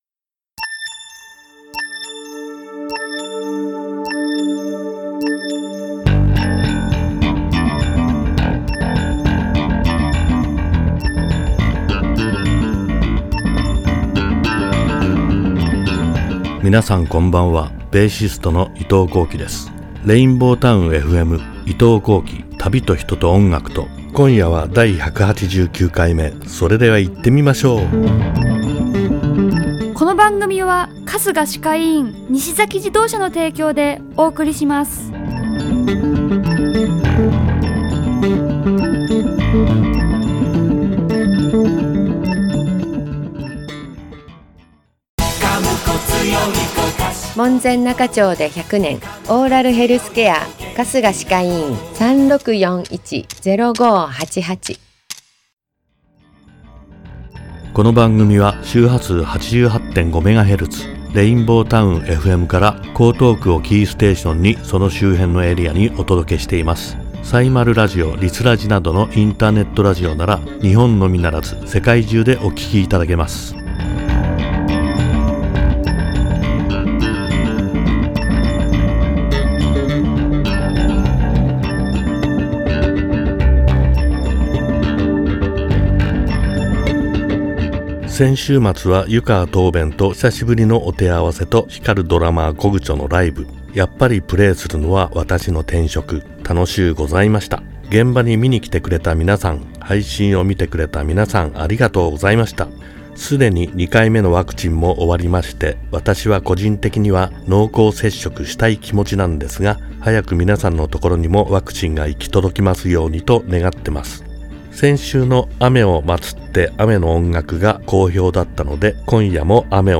※アーカイブ・オンデマンドでは、トーク内容のみで楽曲はかけておりません。ご了承ください。